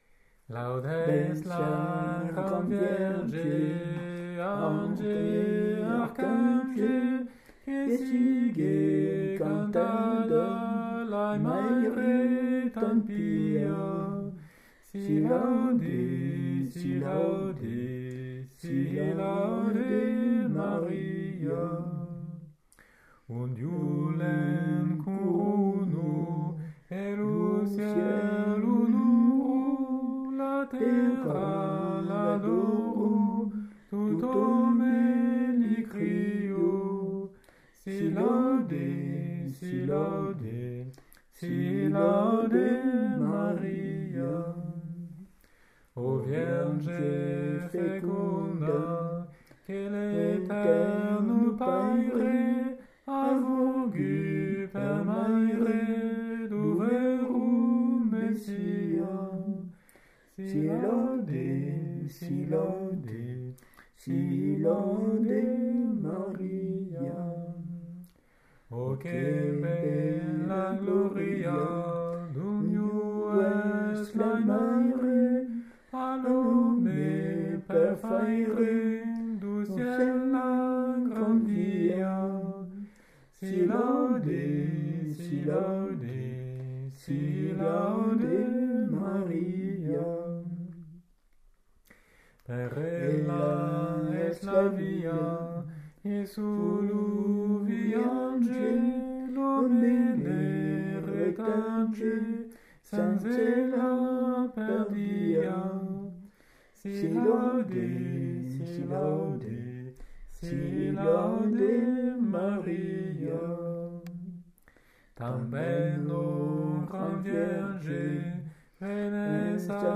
Si laude Maria, chant niçois